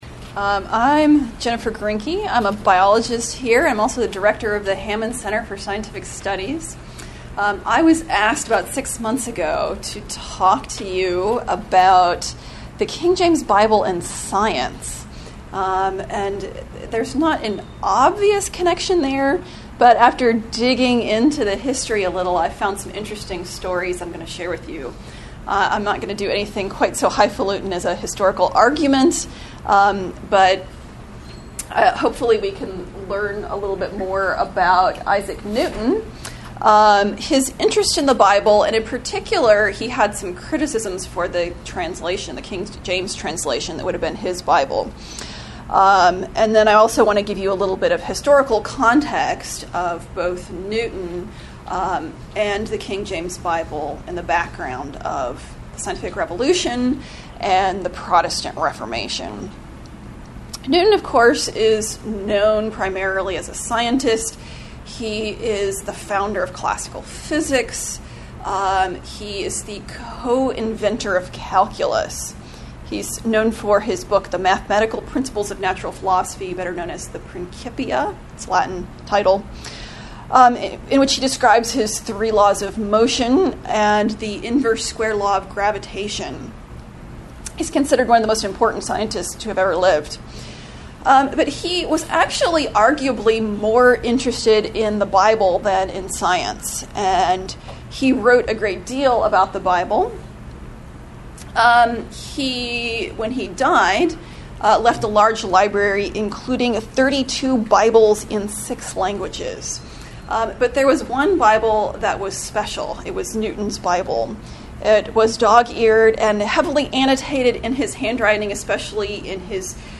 Address: The KJV and the Scientific Revolution